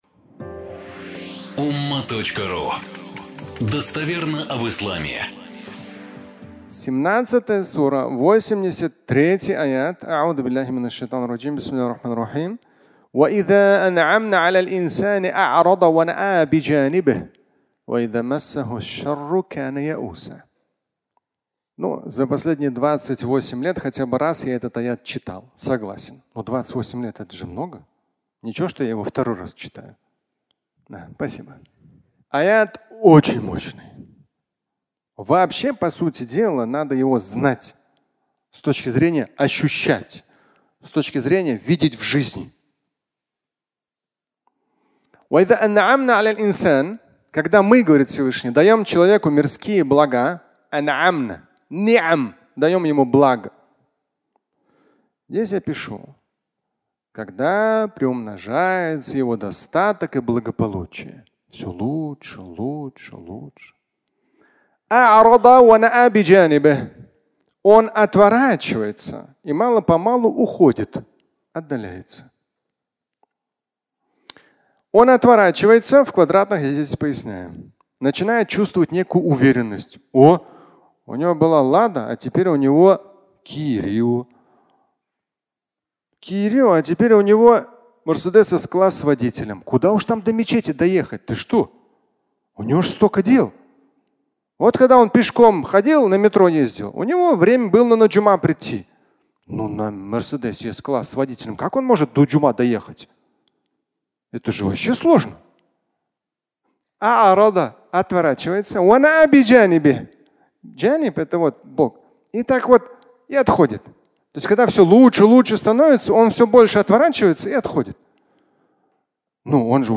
Замминистра (аудиолекция)